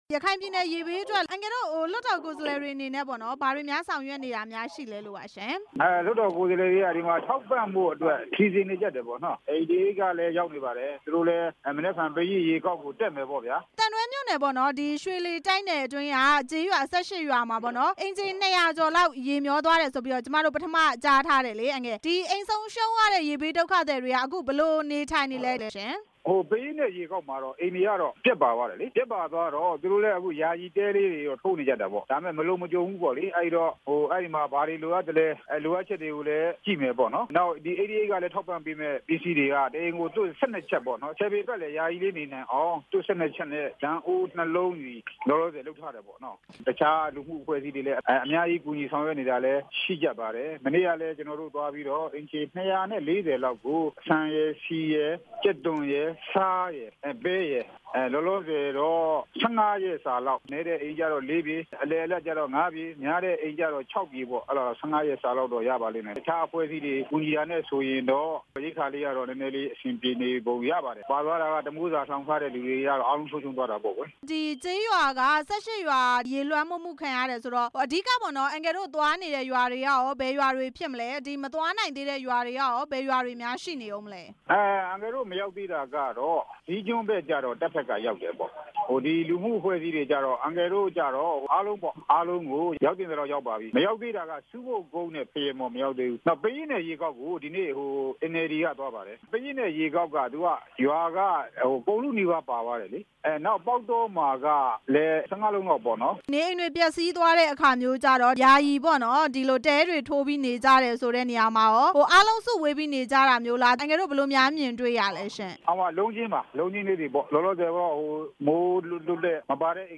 လွှတ်တော်ကိုယ်စားလှယ် ဦးဝင်းနိုင် ကို မေးမြန်းချက်